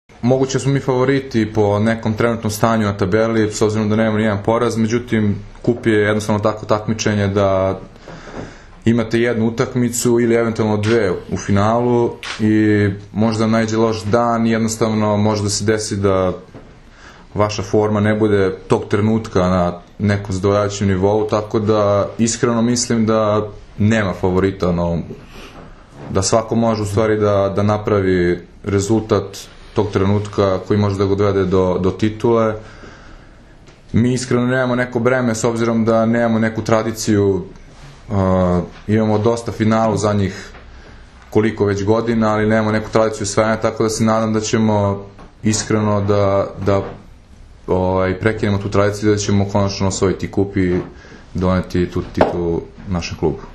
U prostorijama Odbojkaškog saveza Srbije danas je održana konferencija za novinare povodom Finalnog turnira jubilarnog, 50. Kupa Srbije.
IZJAVA